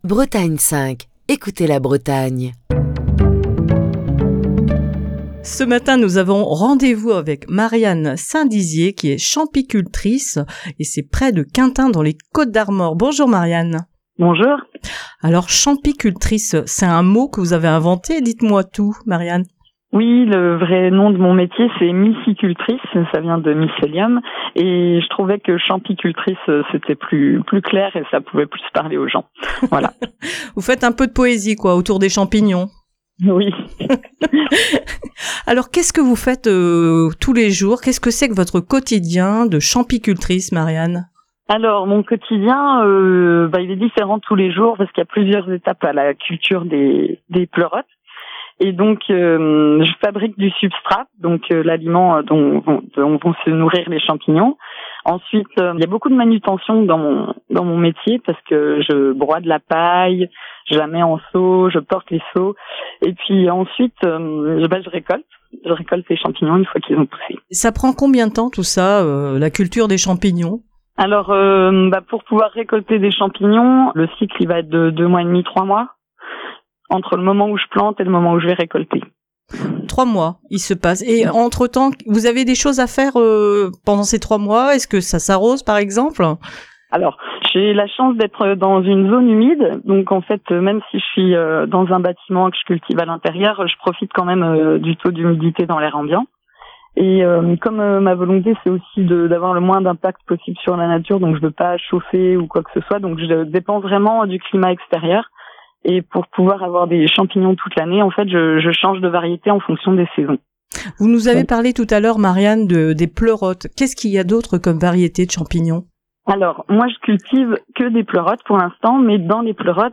est au téléphone